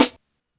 • Sizzle Acoustic Snare Sample B Key 126.wav
Royality free snare drum sound tuned to the B note. Loudest frequency: 2242Hz
sizzle-acoustic-snare-sample-b-key-126-AHw.wav